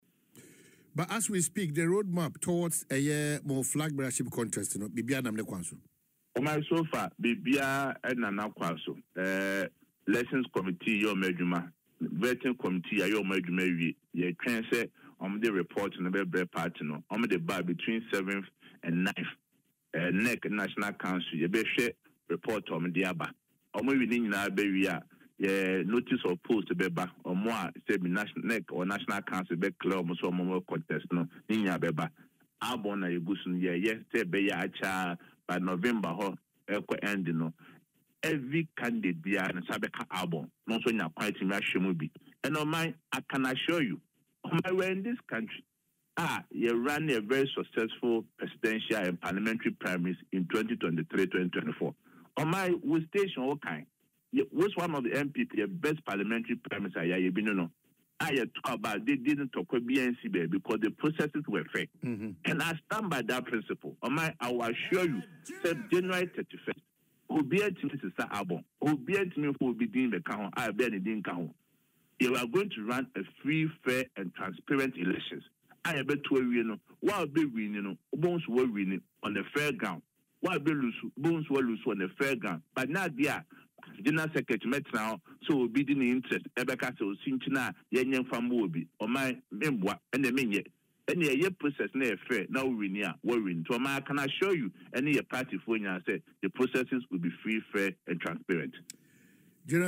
Speaking on Adom FM’s Dwaso Nsem, Mr. Kodua said the party’s internal processes were on track.